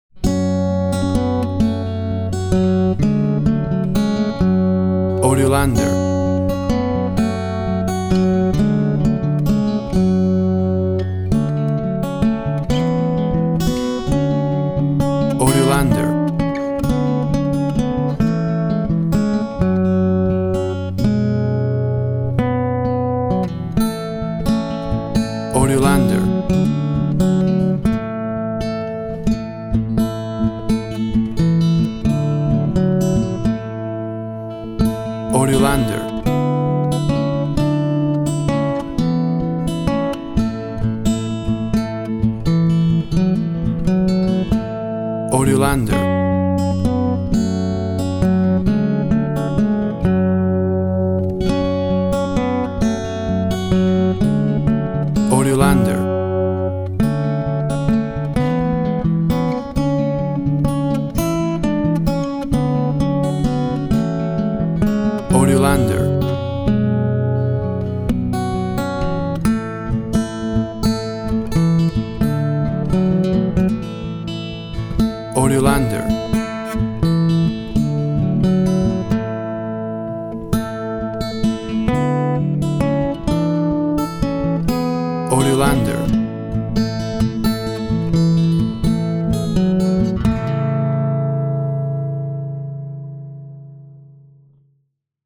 A traditional acoustic guitar version
Tempo (BPM) 130